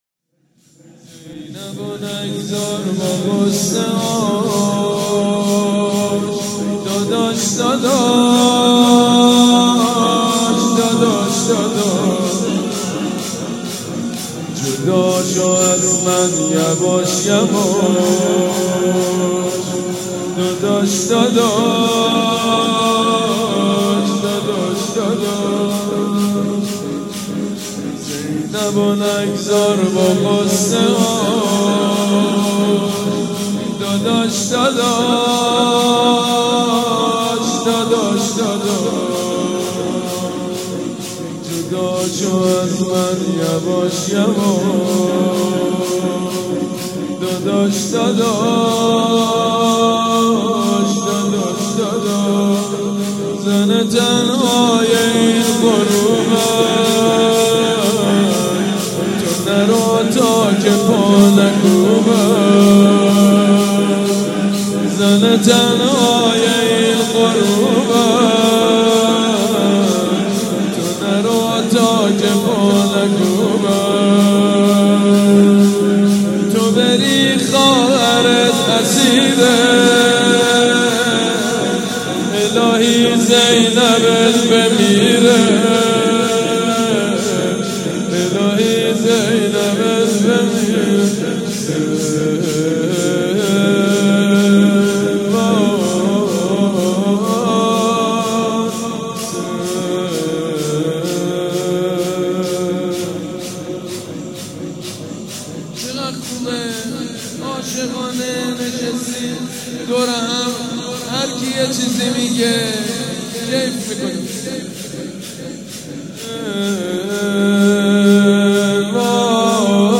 مناسبت : شب هفدهم رمضان
مداح : سیدمجید بنی‌فاطمه قالب : زمینه